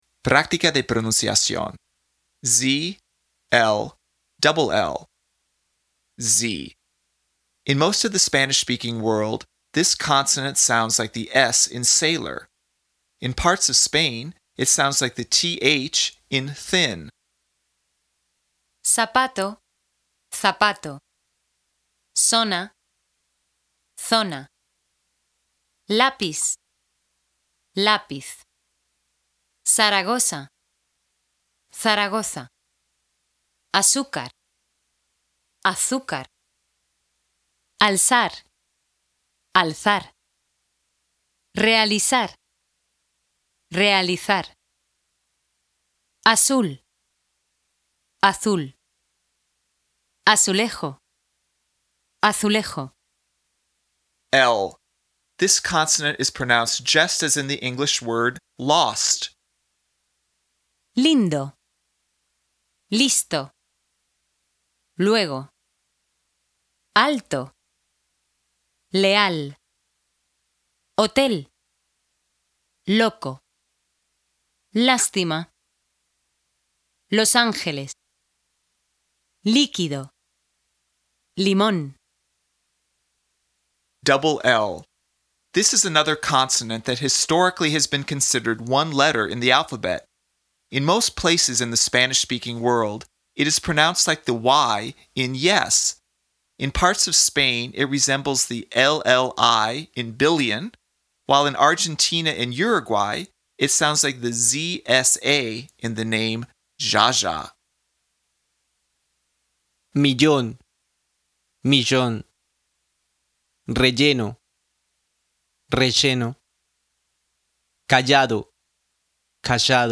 PRÁCTICA DE PRONUNCIACIÓN
In most of the Spanish-speaking world, this consonant sounds like the “s” in “sailor.”
This consonant is pronounced just as in the English word “lost.”